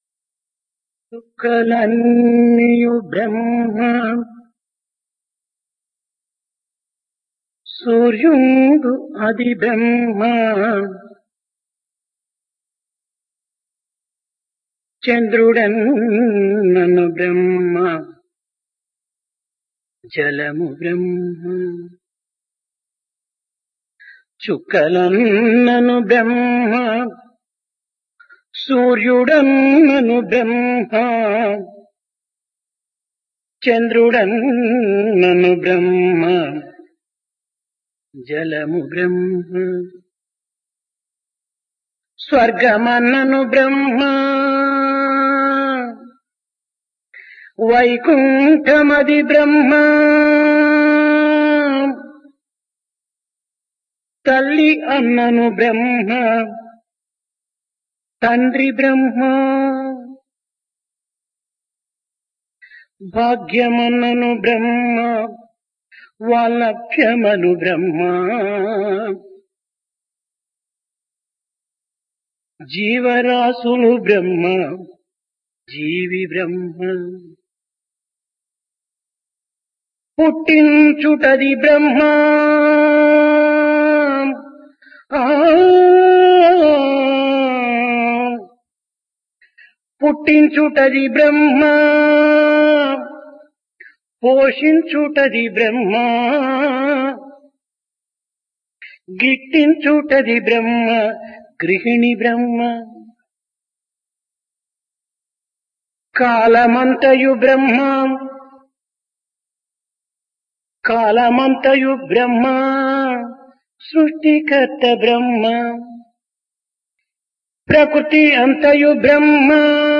Dasara - Divine Discourse | Sri Sathya Sai Speaks
Place Prasanthi Nilayam Occasion Dasara